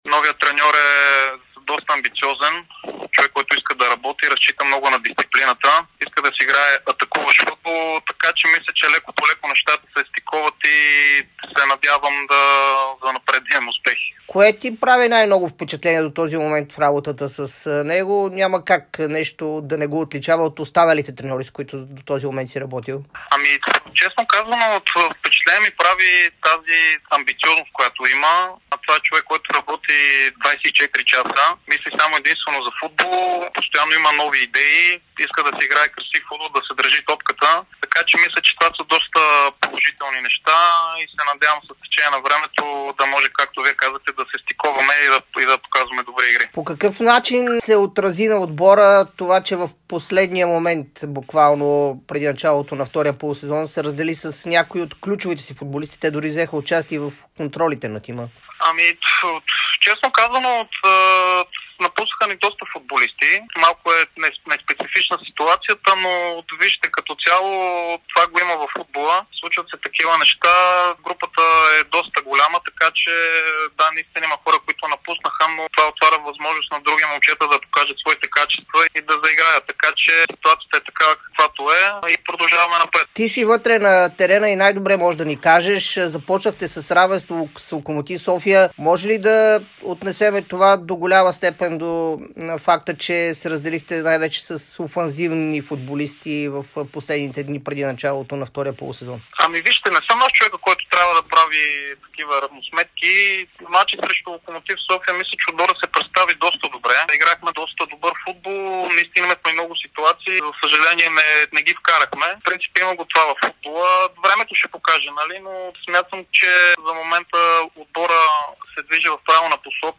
специално интервю пред Дарик радио и dsport